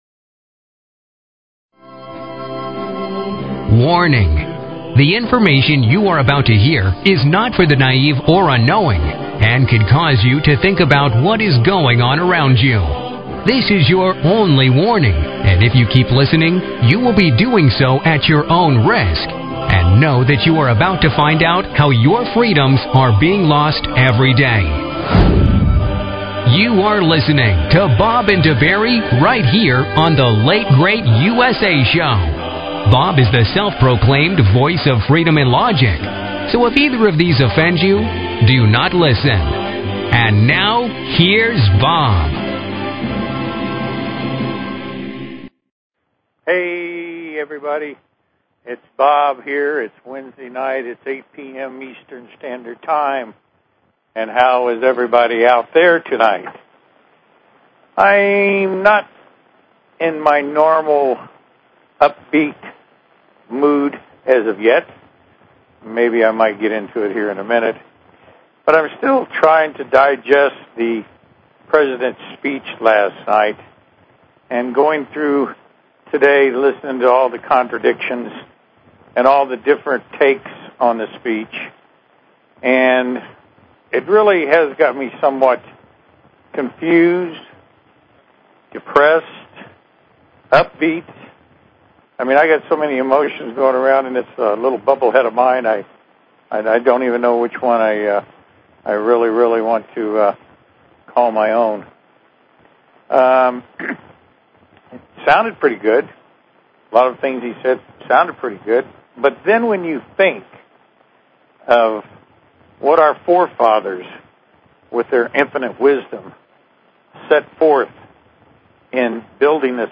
Talk Show Episode, Audio Podcast, The_Late_Great_USA and Courtesy of BBS Radio on , show guests , about , categorized as